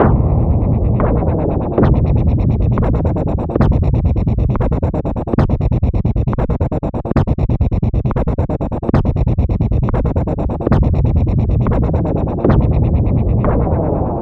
FX 135-BPM 2.wav